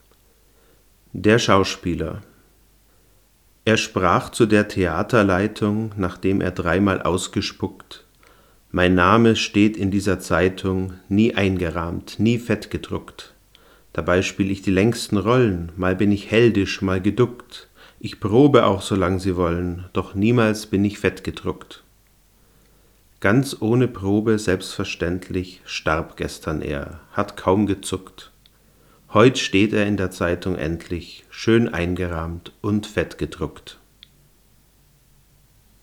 Ich habe jetzt noch eine weitere Reihe gemacht, diesmal mit dem Rode NTG-2 aus ebenfalls ca. 60 cm Abstand.
Mikrofon direkt am Mic-Eingang des Recorders Tascam DR-40
tascam_dr40.mp3